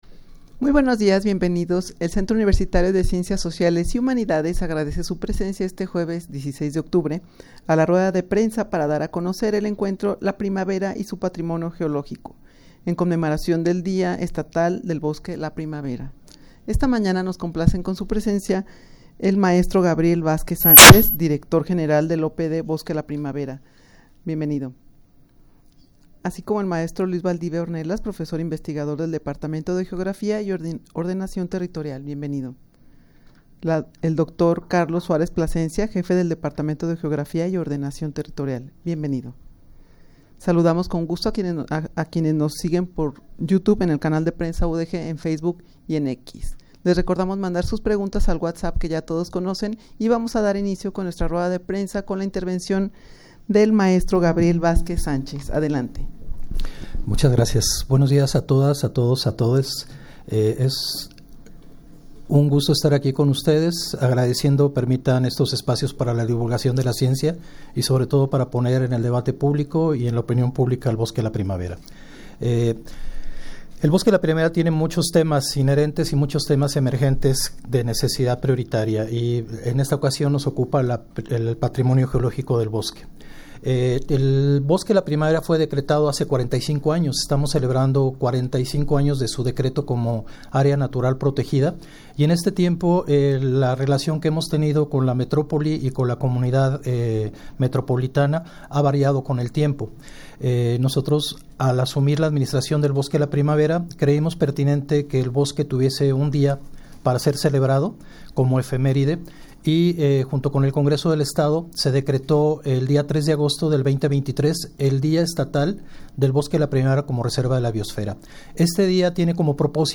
Audio de la Rueda de Prensa
rueda-de-prensa-para-dar-a-conocer-el-encuentro-la-primavera-y-su-patrimonio-geologico.mp3